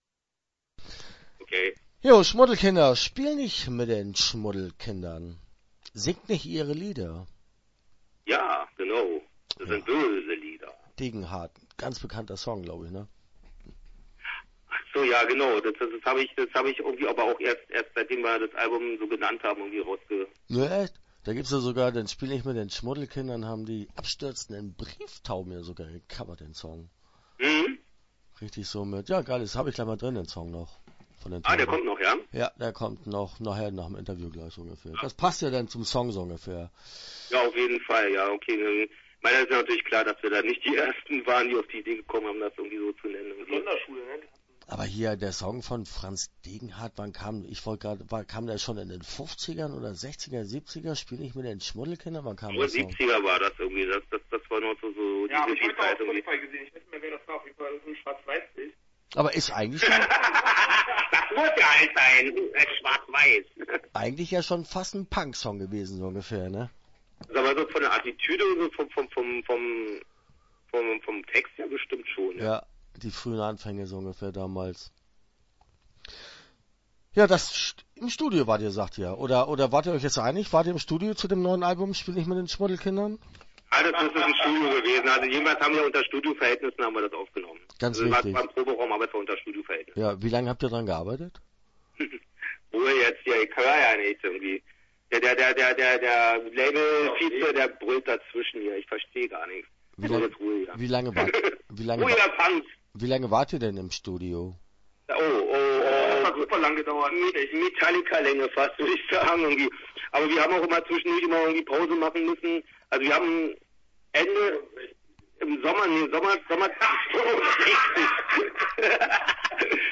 Pommes oder Pizza - Interview Teil 1 (10:56)